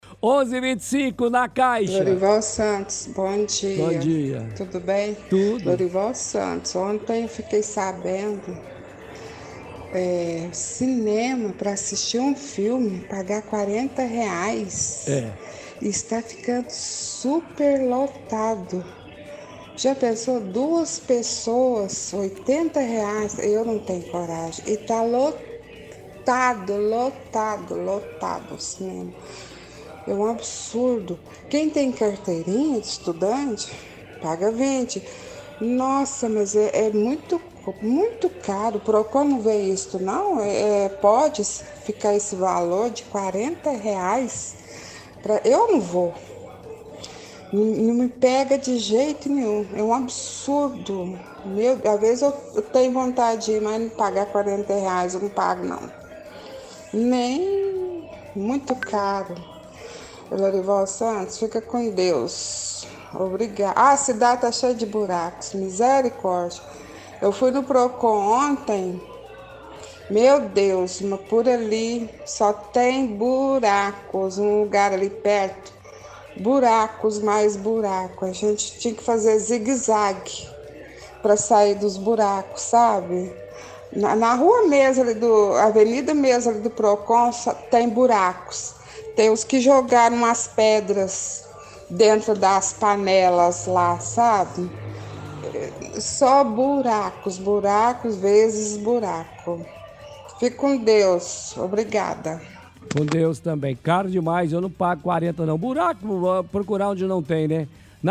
– Ouvinte reclama de o preço do cinema estar R$ 40 reais e questiona se o Procon não está vendo isso.